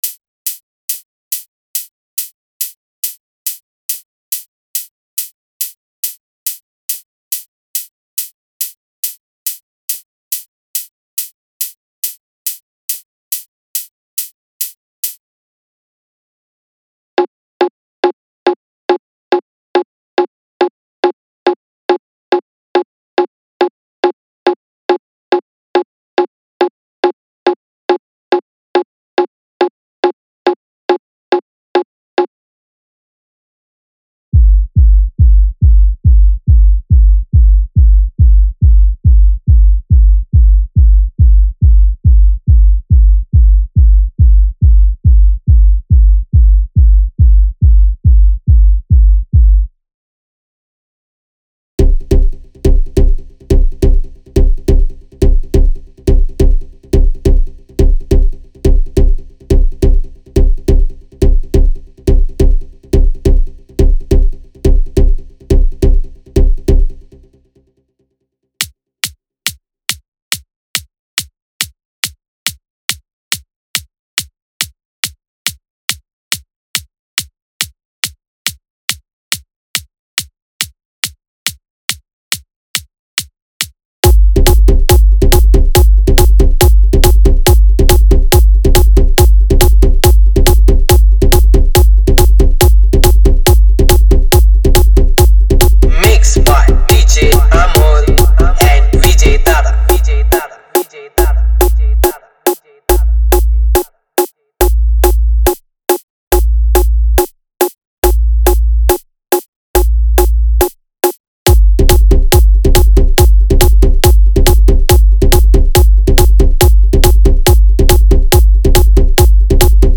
Marathi Sound Check 2025
• Category:Marathi Single